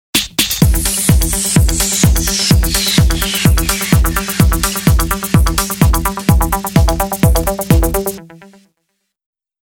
サイドチェインのサンプル
（音がかなり大きいのでボリュームを下げて聴いてください）
↑このサンプルは「シュゥ～」というSEを、コンプレッサーのサイドチェインを使って「シュワッシュワッ」とキックに合わせて音が下がるようにしたものです。エレクトロ系の曲によくあるサウンドですね。
sidechain-sample.mp3